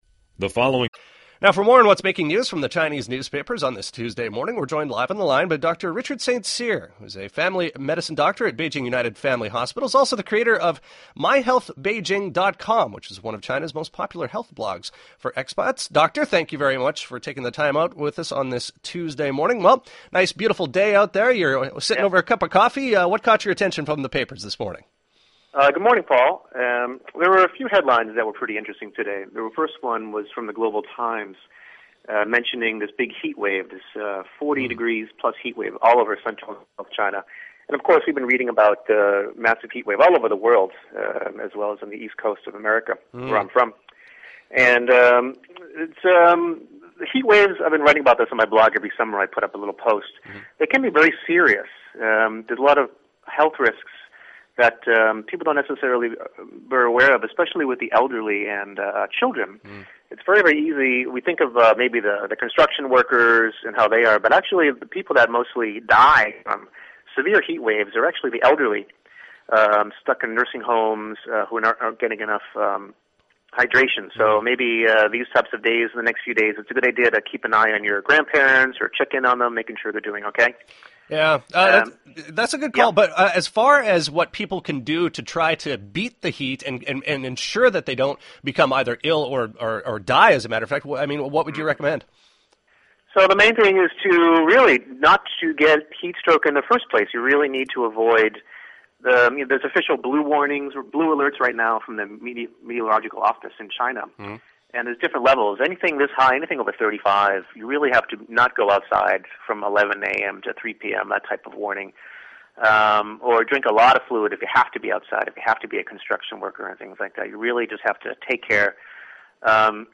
EZFM is the popular bilingual radio station on the CRI Radio network, broadcasting here in Beijing and on multiple stations all over the world, as well as live online here.